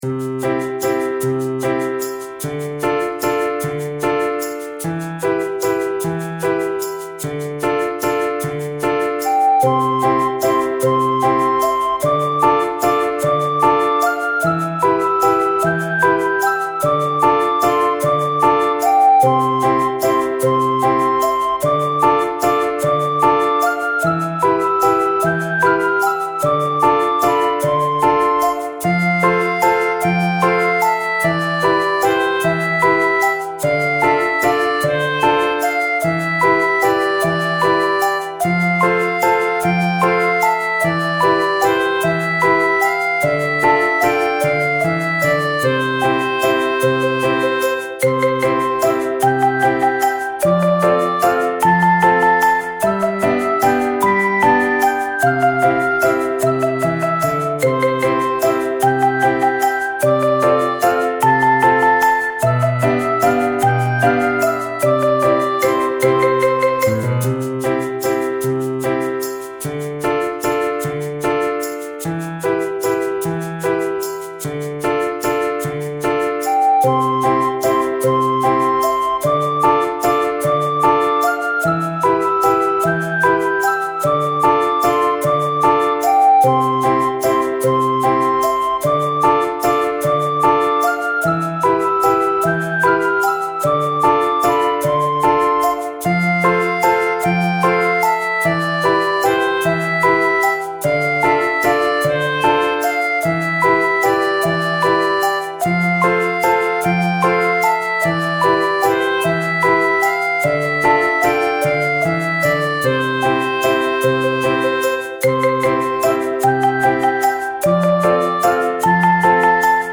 幼稚園や小学校低学年の子どもたちが遊んでいるイメージのBGMです。ほのぼのした雰囲気の場面に合うと思います。